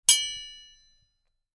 5_Knife_Slash_E
clink ding knife slash sword sound effect free sound royalty free Sound Effects